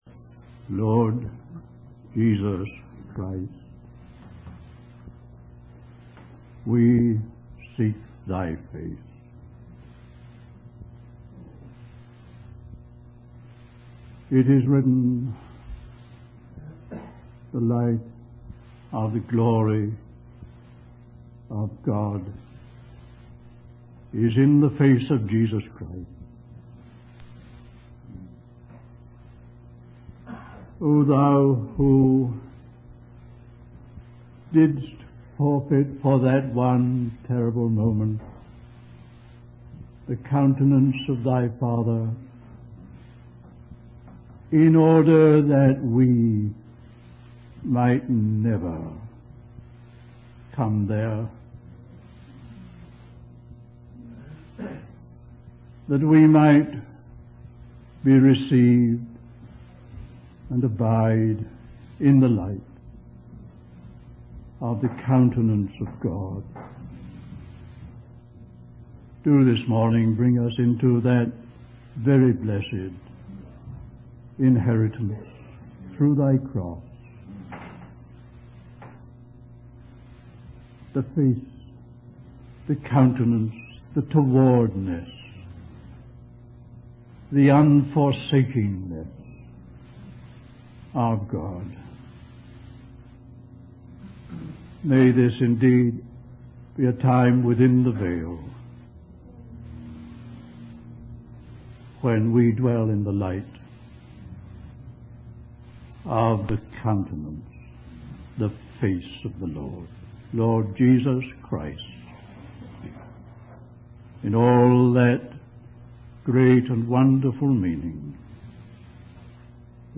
In this sermon, the speaker uses the analogy of a boat mooring to illustrate the importance of not drifting away from the truth of Christ. He emphasizes the need to hold on tightly to the teachings of Christ and not be carried away by the currents of the world. The speaker also highlights the contrast between transient and permanent things, urging the listeners to focus on the things that cannot be shaken.